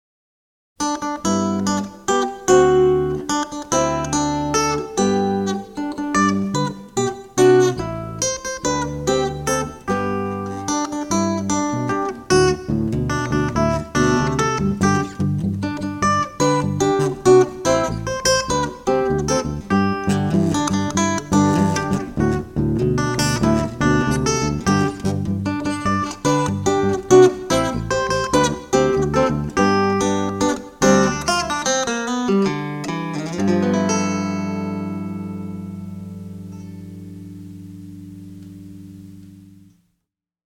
Acoustic / electric tin box guitar
Maybe with a blues version of
Happy birthday to you performed on tin box guitar: